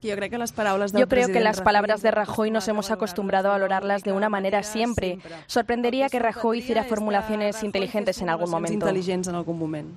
Anna Gabriel, diputada de la CUP
La CUP y otras organizaciones vinculadas a la izquierda independentista como Arran o Endavant han presentado este jueves en rueda de prensa, frente a la sede de la Cámara de Comercio de Barcelona, su campaña conjunta para el referéndum del 1 de octubre bajo el lema "Autodeterminación, desobediencia, Países Catalanes: barrámoslos".